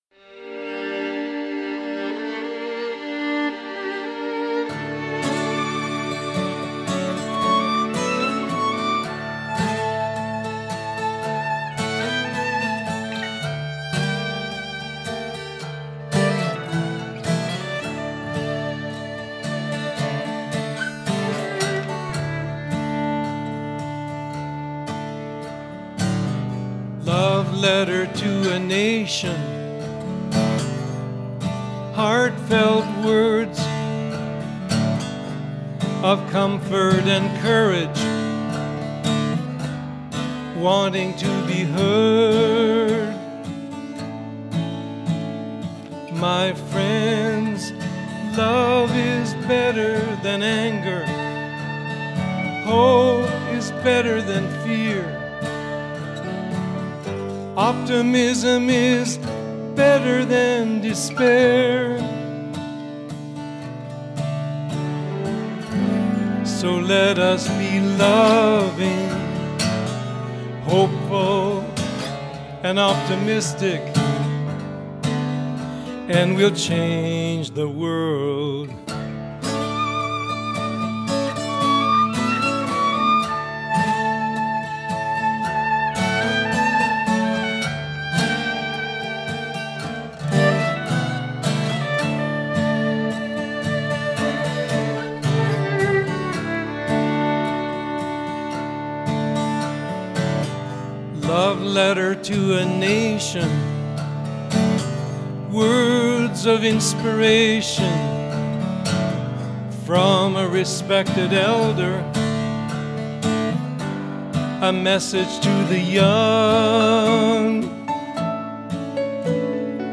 Genre: Contemporary Folk